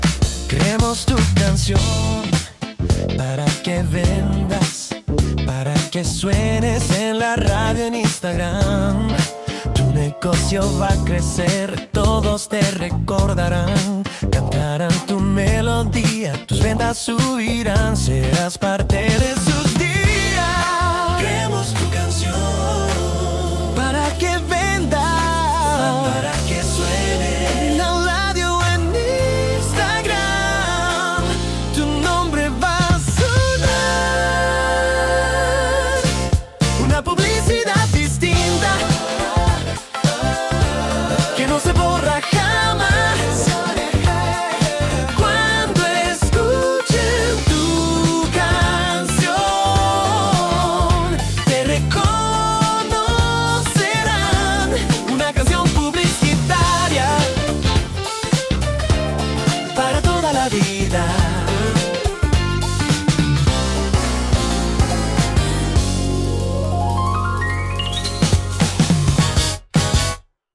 Pop moderno
Pop moderno – Voz masculina